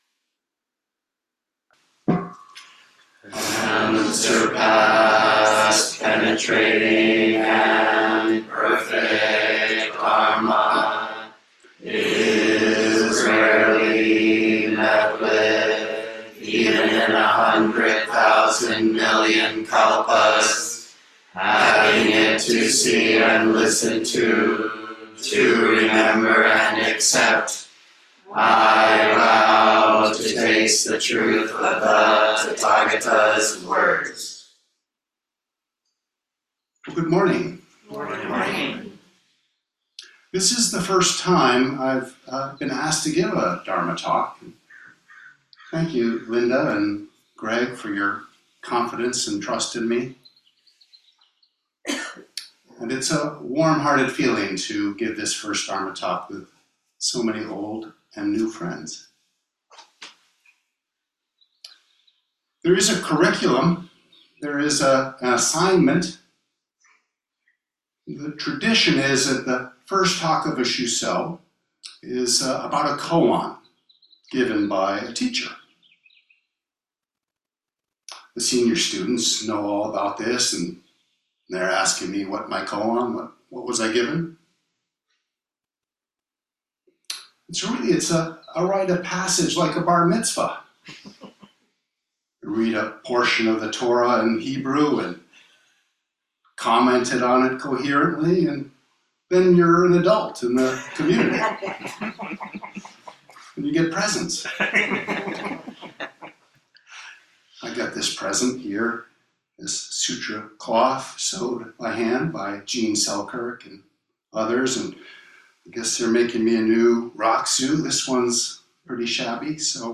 Lectures, talks, and classes on Zen Buddhism from Shogakuji Temple, Berkeley Zen Center, in Berkeley, California, USA.